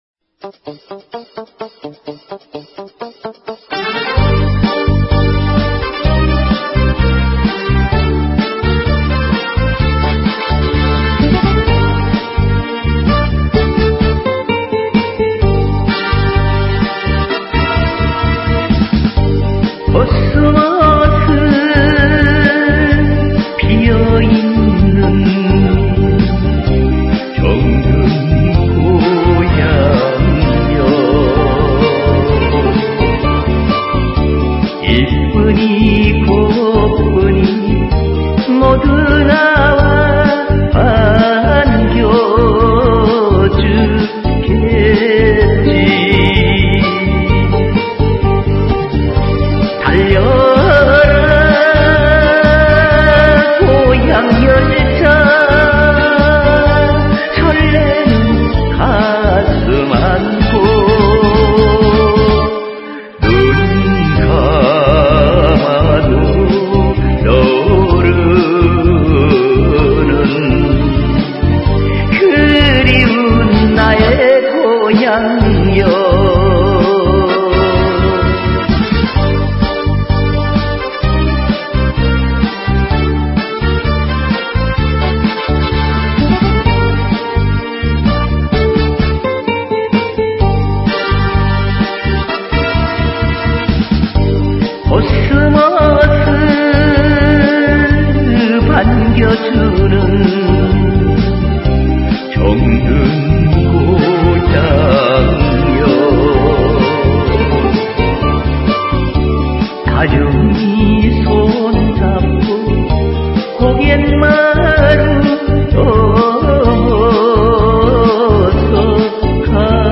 디스코